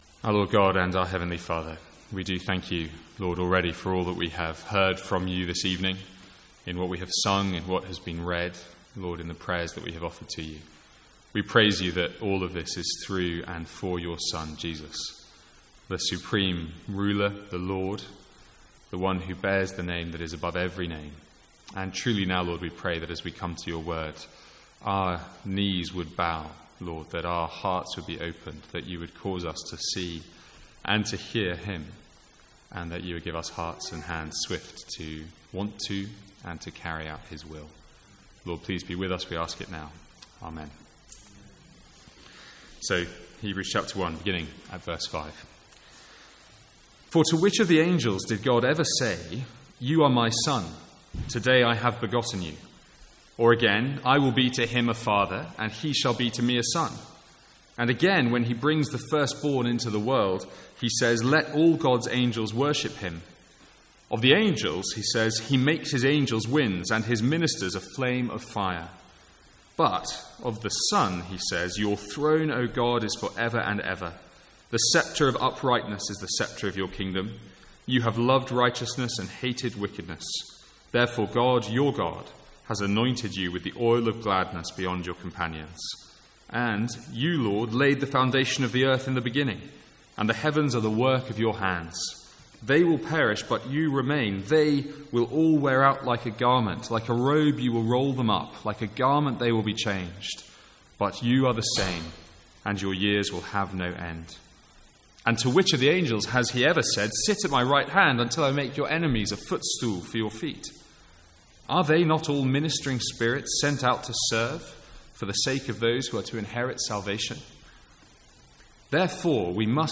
From the Sunday evening series in Hebrews.